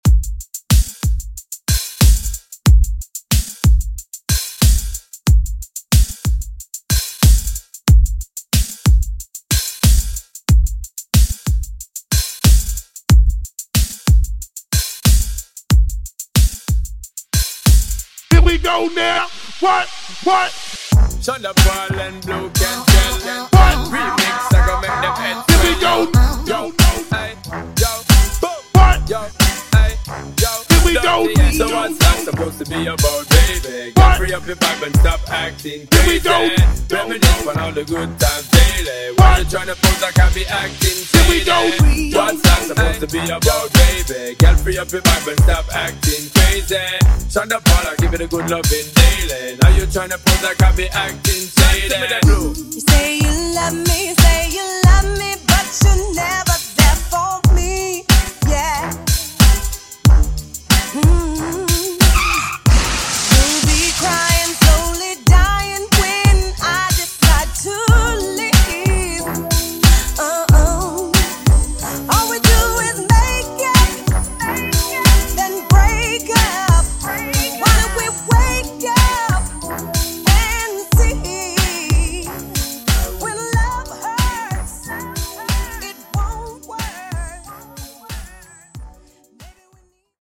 • (Audio & Video Editor) Open Format Dj